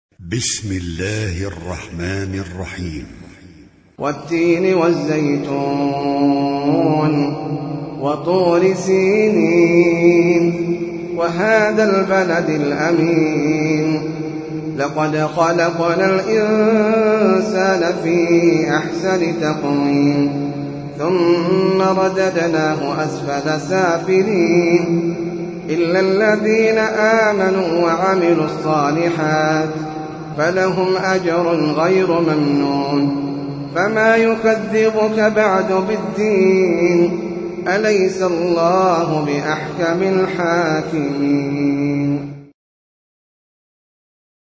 سورة التين - المصحف المرتل
جودة عالية